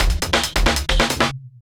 drums02.wav